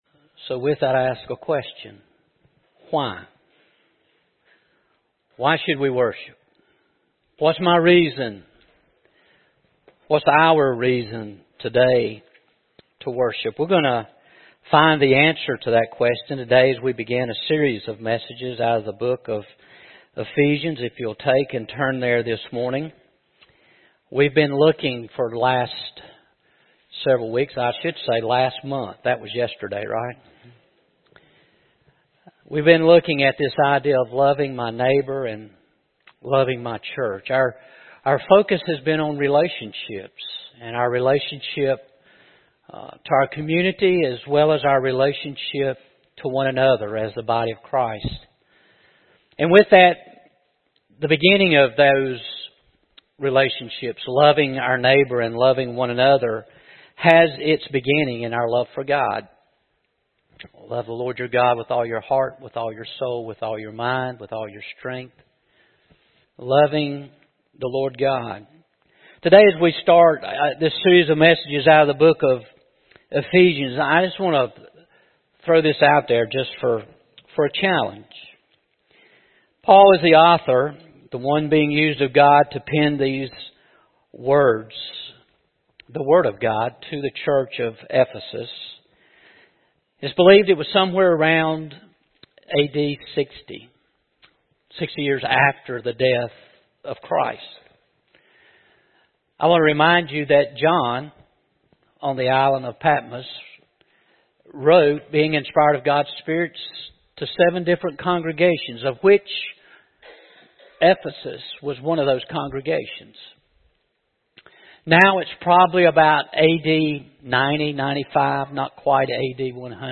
First Baptist Garner > Watch > Sunday Sermons